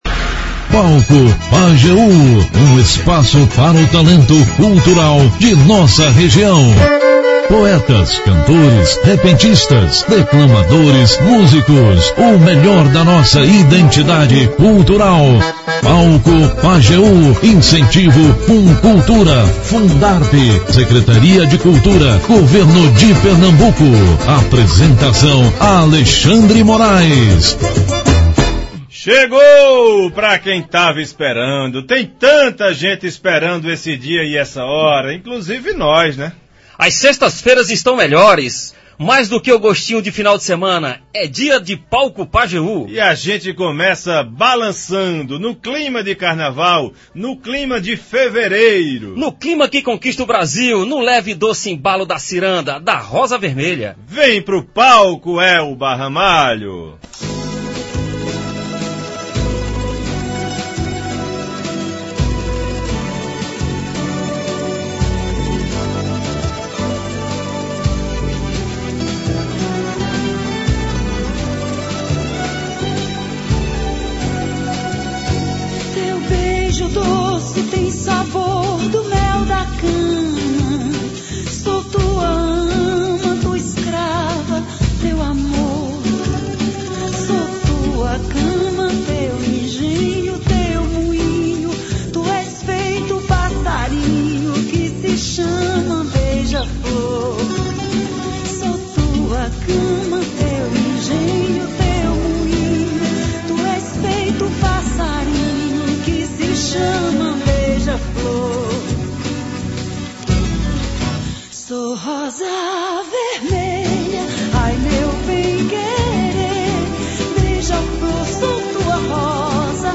O Palco Pajeú é um espaço para o talento cultural da região, com poetas, cantores, repentistas, declamadores, músicos e o melhor da identidade cultural do sertanejo.